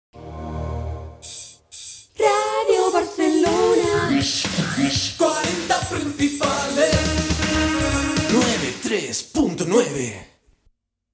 Indicatiu del programa i de l'emissora